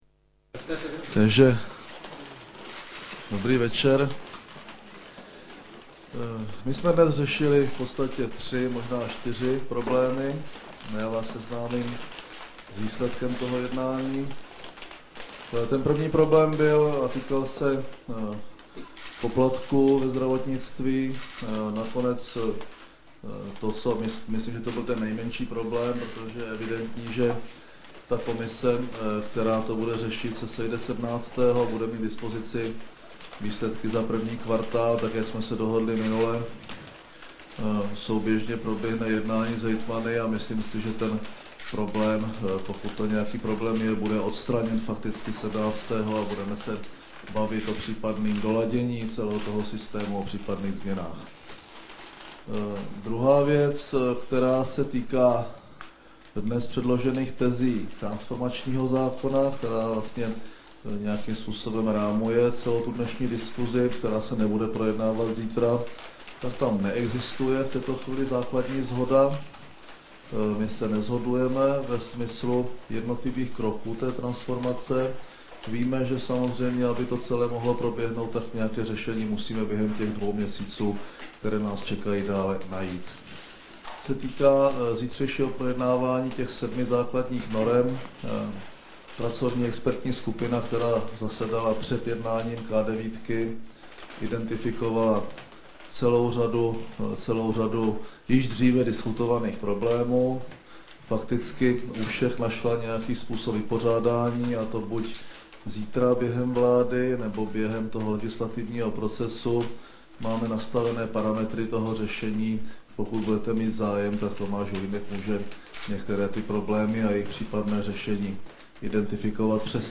Tisková konference po jednání koaličních stran 8. dubna 2008 v Kramářově vile - K9